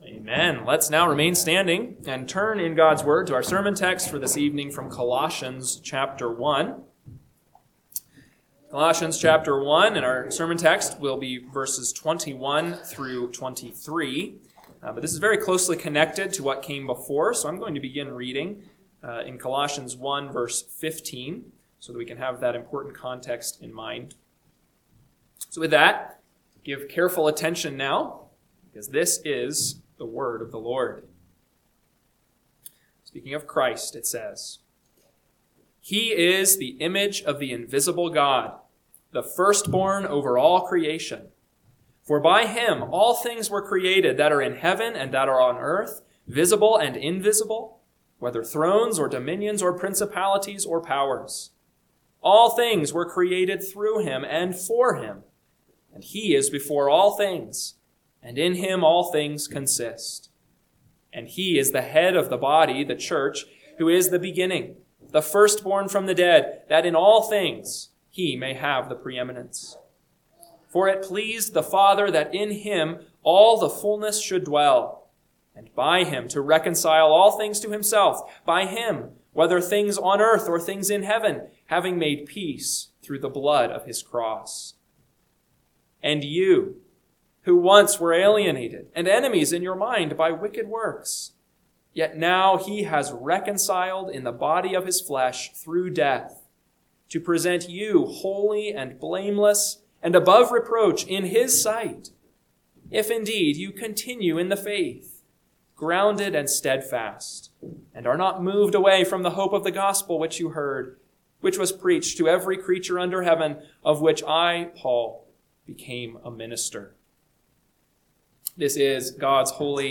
PM Sermon – 1/25/2026 – Colossians 1:21-23 – Northwoods Sermons